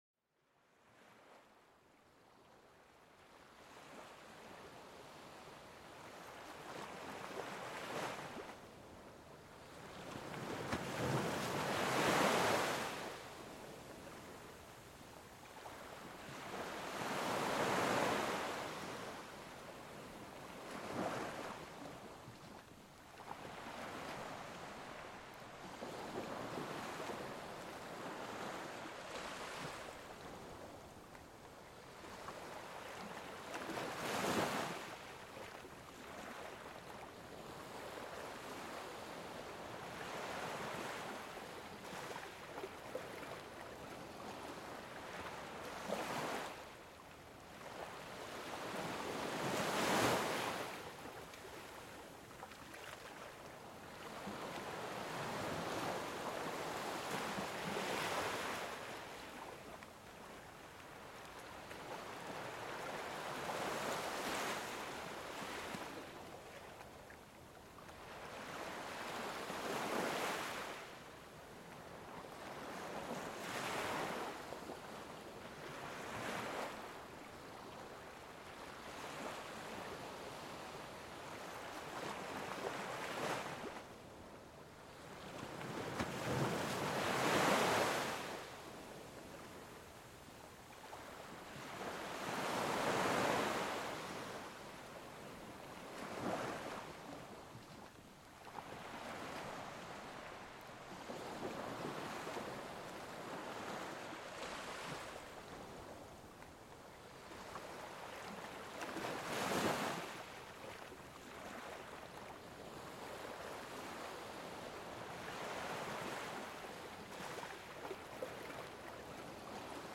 Vagues apaisantes de l'océan : Relaxation et sérénité assurées
Dans cet épisode, plongez-vous dans le doux son des vagues de la mer, un véritable bain de tranquillité pour l'esprit. Le rythme régulier de l'eau qui caresse le rivage vous enveloppera dans une atmosphère paisible et relaxante.